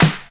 • Fresh Round Snare Drum Sample D# Key 208.wav
Royality free acoustic snare tuned to the D# note. Loudest frequency: 1901Hz
fresh-round-snare-drum-sample-d-sharp-key-208-R75.wav